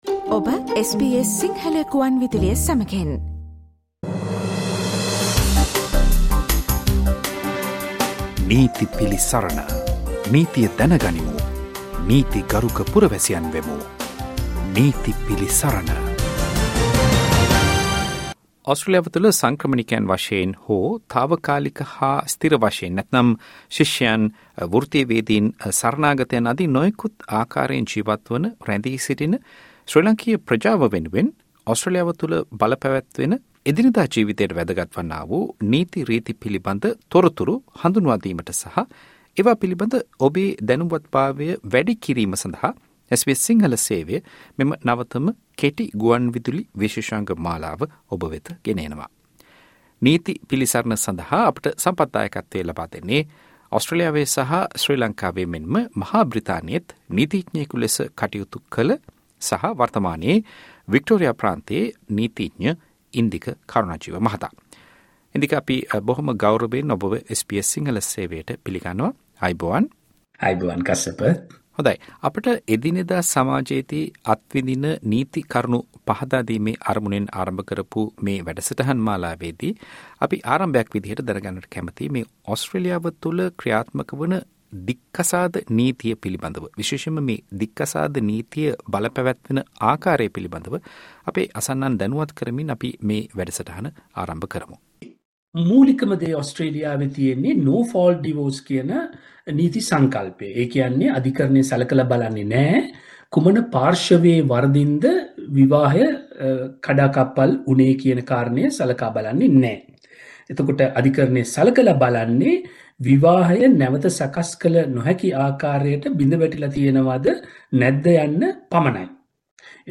ඕස්ට්‍රේලියාවේ ජීවත් වන ඔබට වැදගත් වන නීති කරුණු පැහැදිලි කරන නීති පිළිසරණ විශේෂාංගය. මෙම වැඩසටහන දික්කසාද නීතිය පිළිබඳ සාකච්ඡාවේ පළමු කොටසයි.